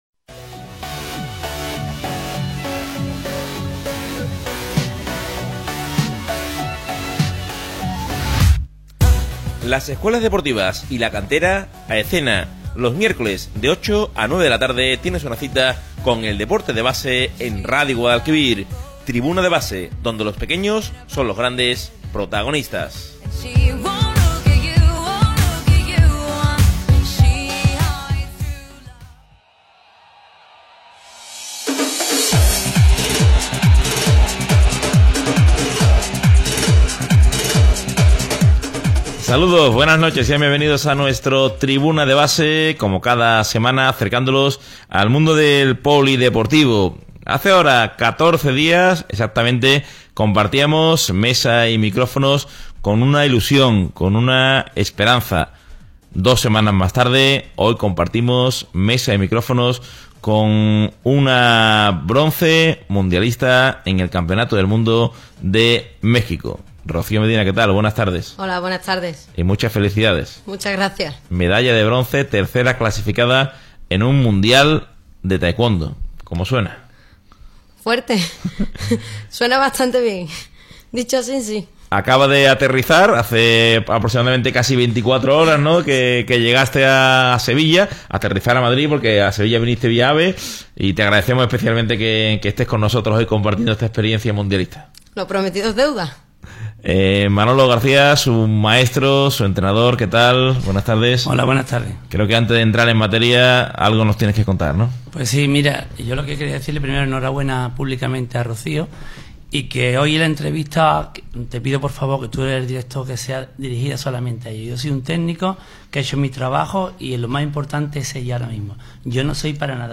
Entrevista homenaje